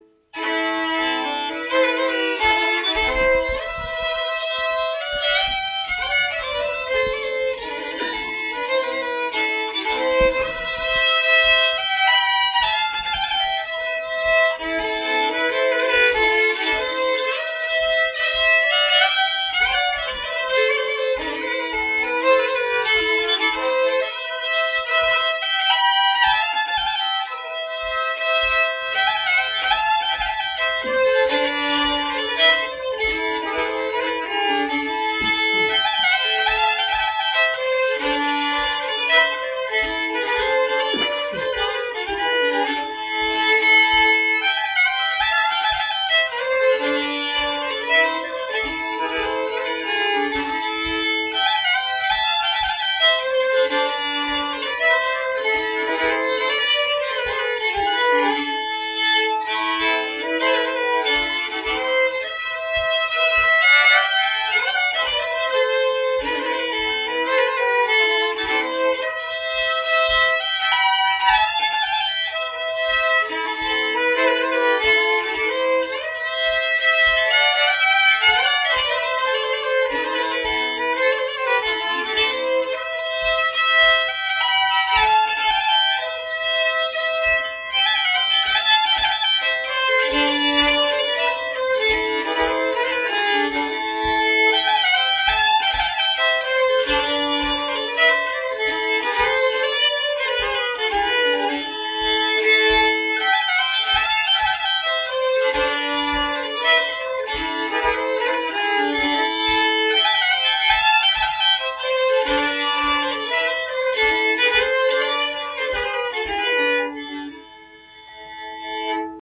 1027 Som Förr, polska